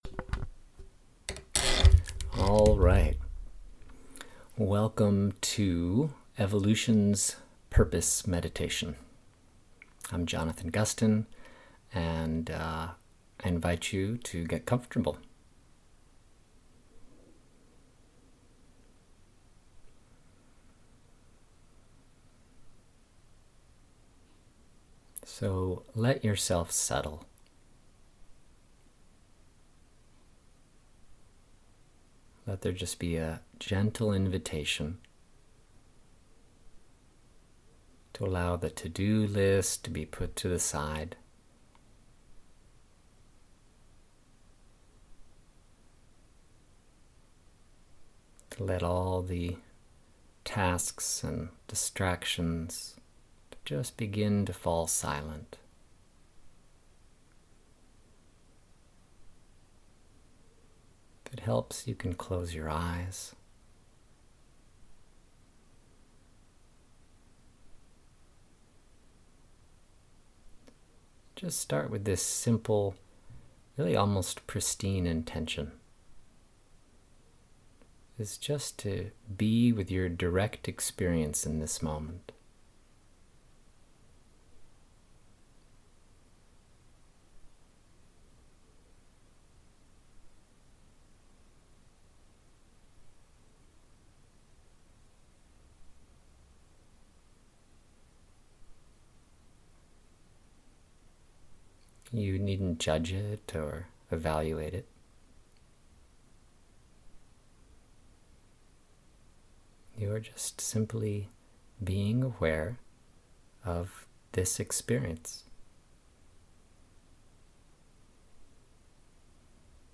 evolutions-purpose-meditation.mp3